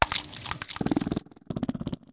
bombirdier_ambient.ogg